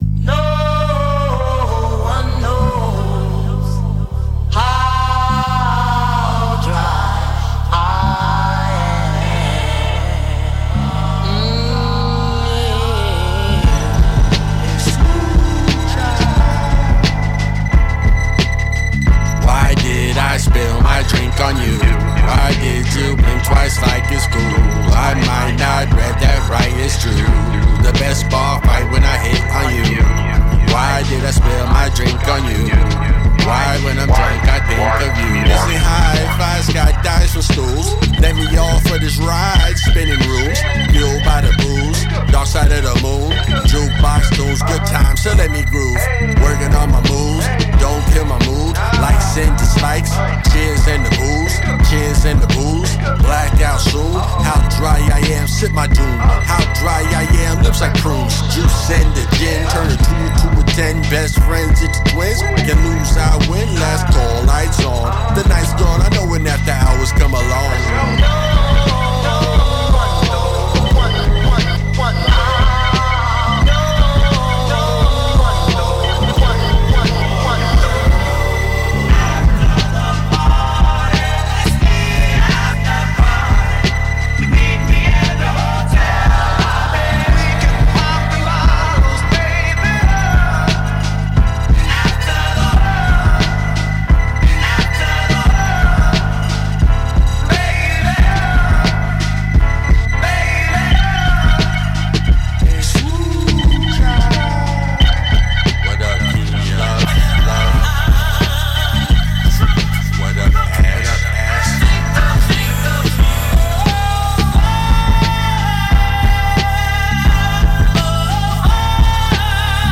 super tipsy, a loveable drunk